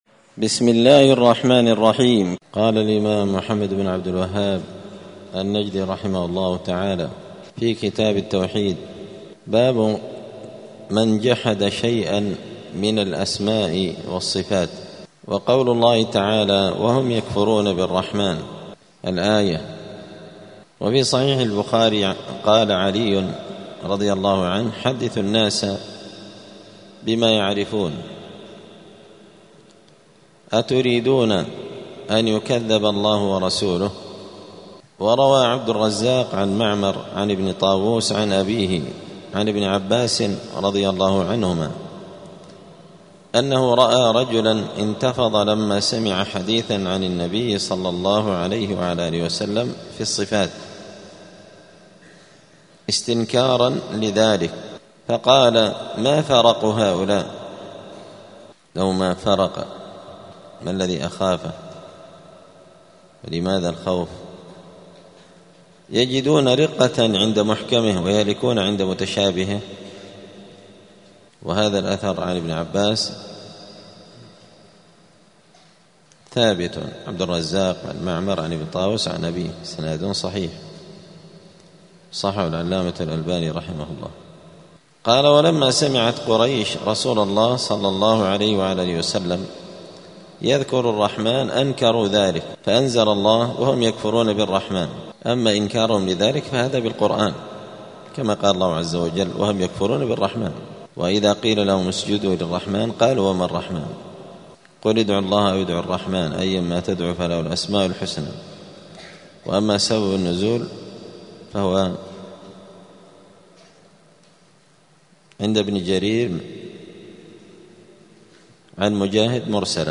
دار الحديث السلفية بمسجد الفرقان قشن المهرة اليمن
*الدرس الرابع عشر بعد المائة (114) باب من جحد شيئا من الأسماء والصفات*